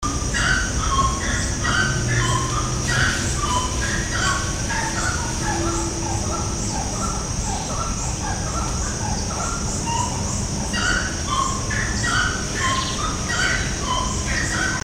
Saracura-três-potes (Aramides cajaneus)
Nome em Inglês: Grey-cowled Wood Rail
Localidade ou área protegida: Isla Tres Bocas, Tigre
Condição: Selvagem
Certeza: Gravado Vocal